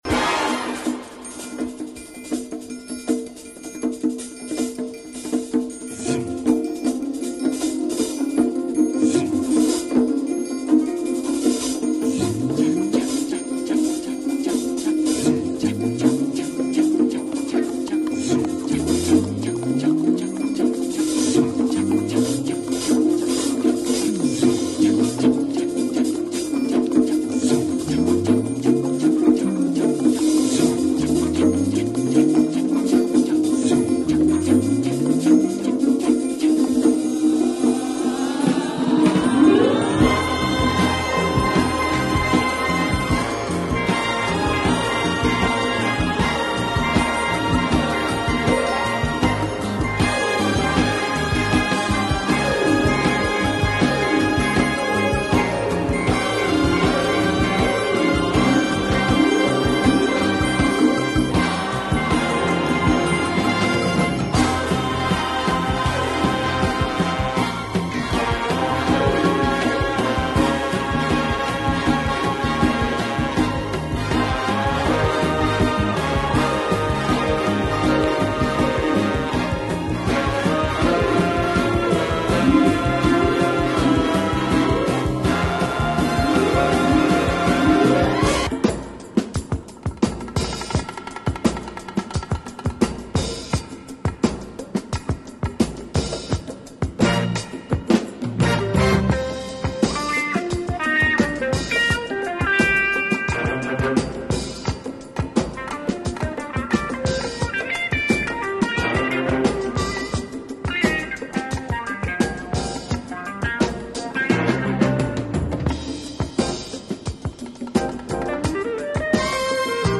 groovy instrumentals